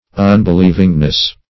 Un`be*liev"ing*ness, n.
unbelievingness.mp3